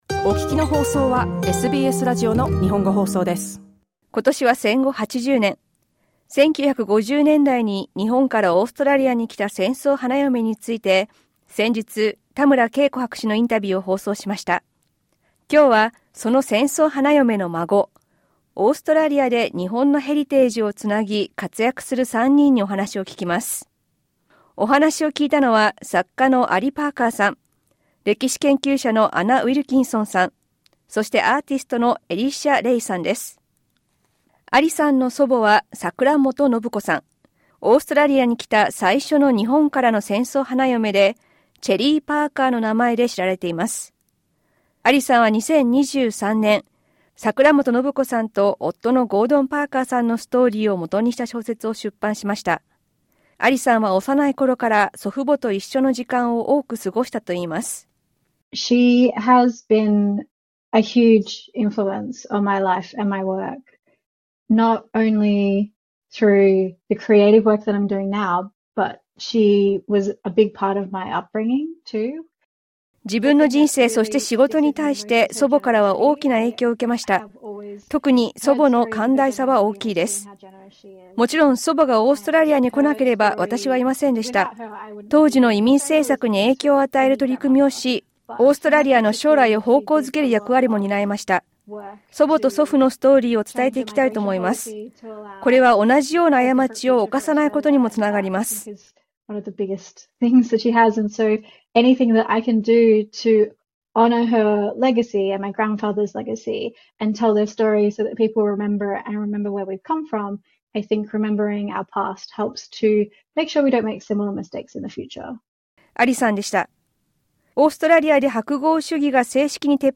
作家・アーティスト・歴史研究家、日本人戦争花嫁の孫たちに聞く